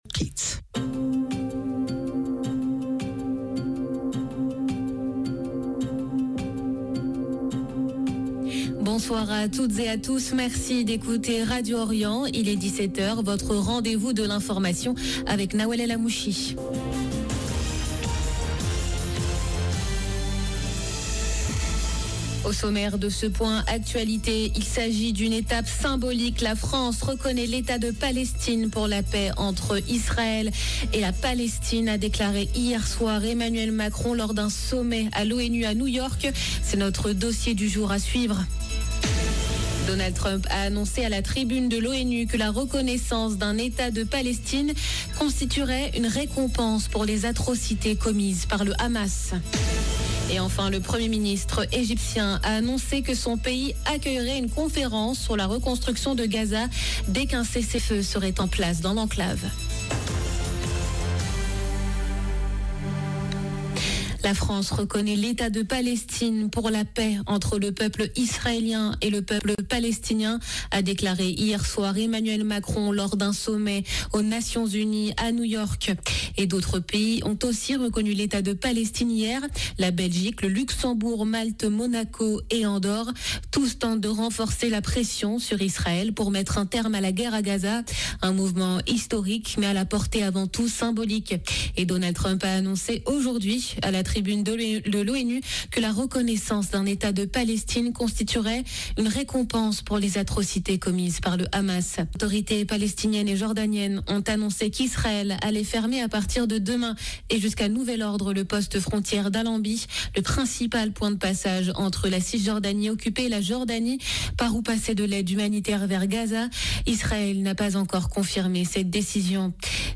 Journal de 17H du 23 septembre 2025